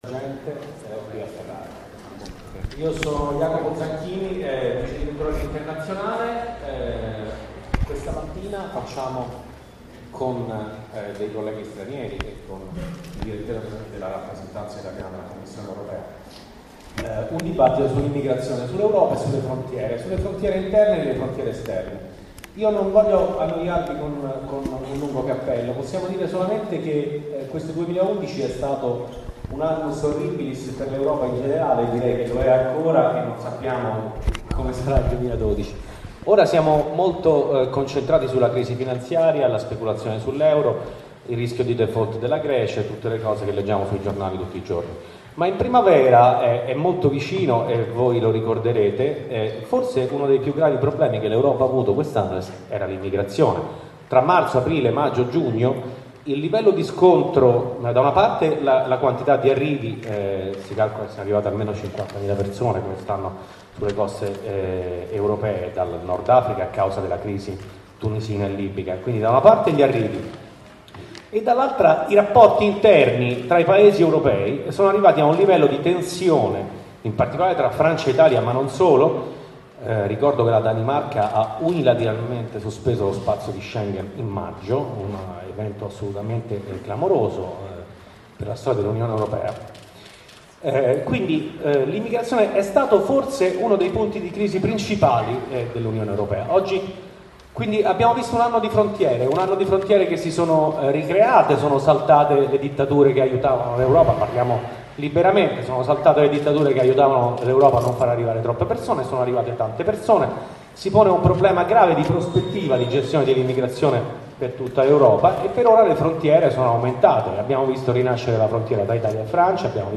INTERNAZIONALE A FERRARA 2011